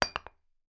Empty Glass Bottle Drop of Floor
alcohol bottle carpet ding drink drinking drop dropped sound effect free sound royalty free Sound Effects